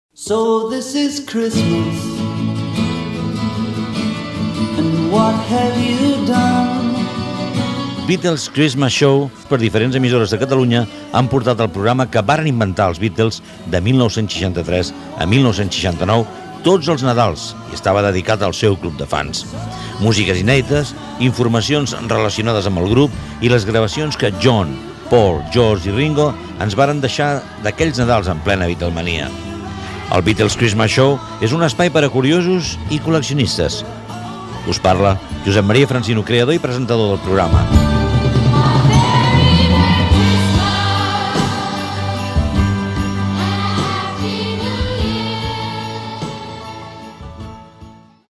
Presentació del programa
Musical